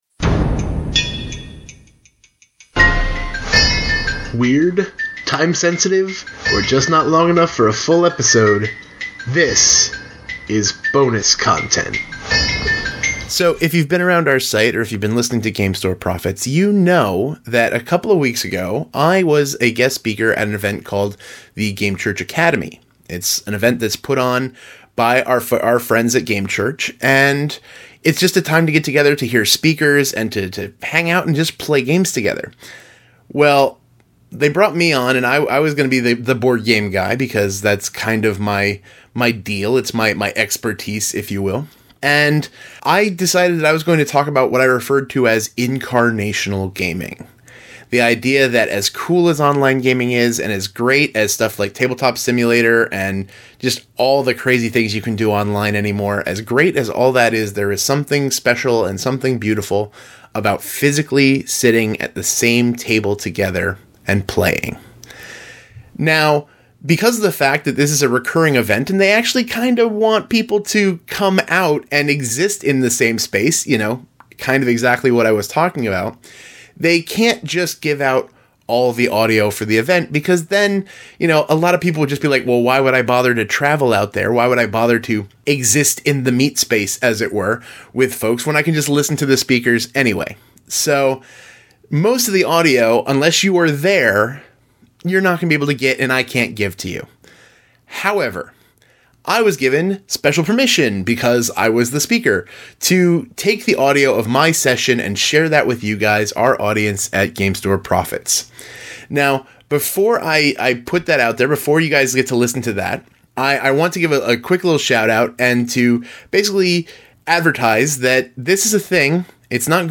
Just a few short weeks ago I was hanging out with folks in Whippany, New Jersey to talk about tabletop gaming and it’s powerful place in ministry contexts. My topic was what I referred to as ‘incarnational gaming’ – aka how online gaming is great, but nothing beats sharing physical space with those you play with.
However, I got special permission to share the audio of my session with our listeners.